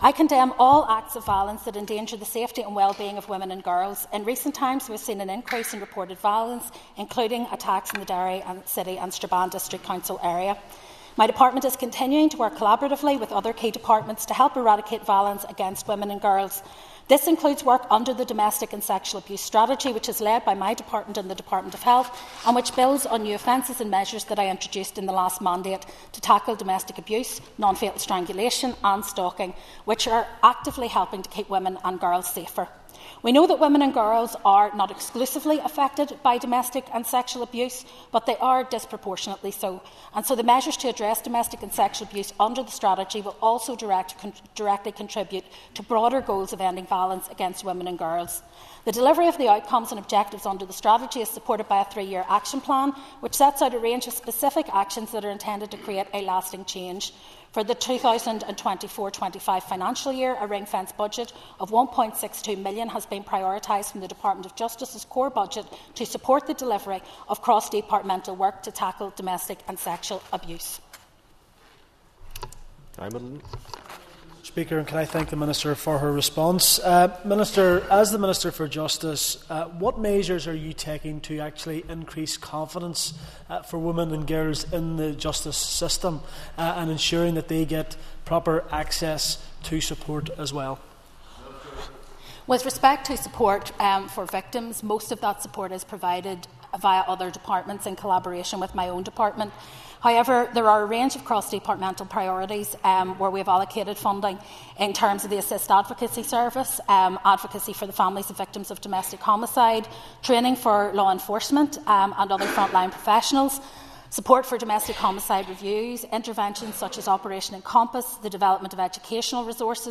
Minister Naomi Long was answering questions in the Assembly from Foyle MLA Gary Middleton and East Derry MLA Cara Hunter………